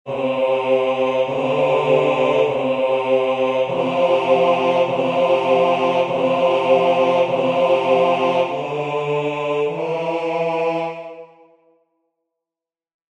Die ganze Tonfolge bewegt sich in parallelen Quarten mit darüber liegenden Quinten (Quarten und Oktaven), wie folgendes Beispiel nach Gerbert (II. 21) zeigt:
Die Distinktion Beispiel 2a) steht im deutero E, in qua ditoni occursus vel simplex vel intermissus placet; 2b) in proto D, mit einem occursus im Einklang am Ende; in der Distinktion 2c) steigt das Organum über den Gesang und während es am Schluss das f hält, macht die Oberstimme eine Art Neuma; in 2d) liegt es, wie eine Art Falso bordone, auf demselben Ton, während der Gesang auf- und abwärts sich hindurchbewegt.
Diaphonie, Notenbeispiele nach Guido von Arezzo